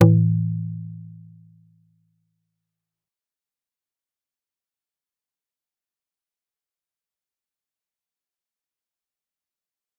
G_Kalimba-A2-f.wav